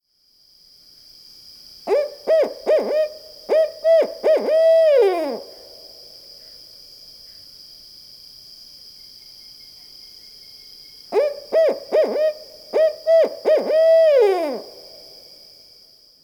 Barred Owl "Who cooks for you, who cooks for you awl"